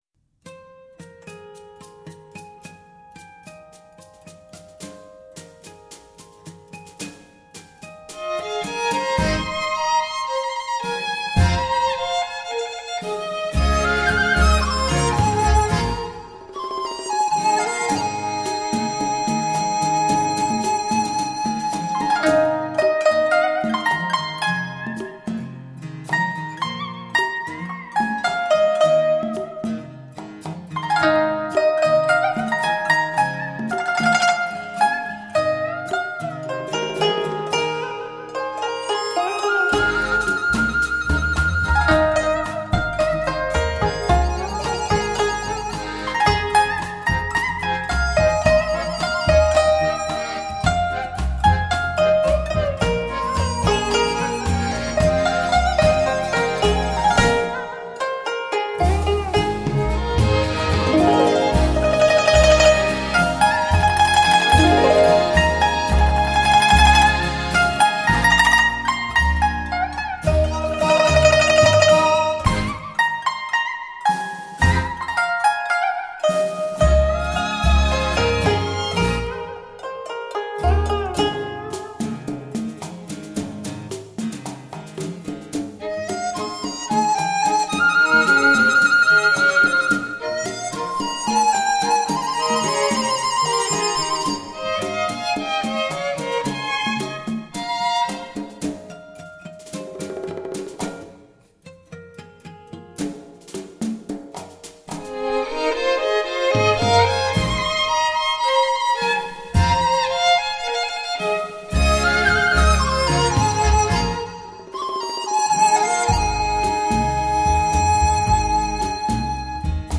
国乐之宝 盛宴之乐 国内大师级演奏家汇聚一席
东方乐器与西方乐器完美对接 树立中国民歌改编音乐版本之典范